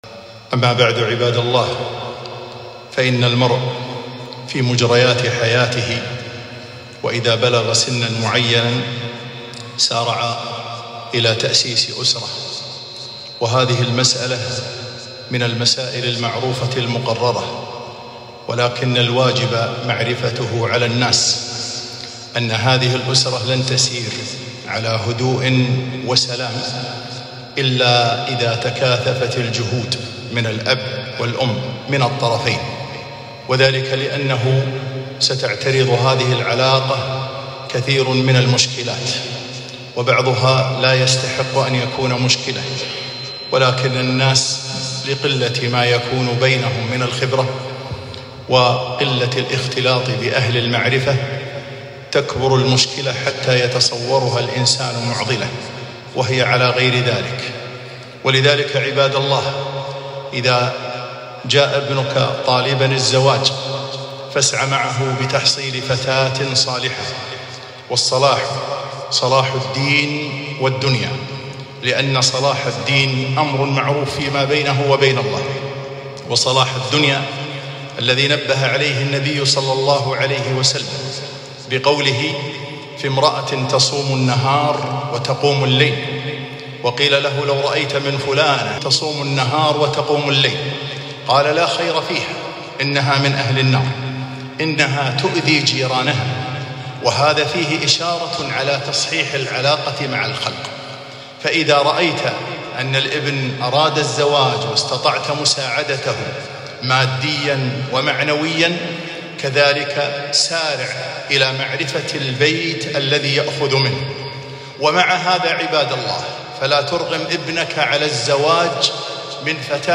خطبة - من أجل زواج آمن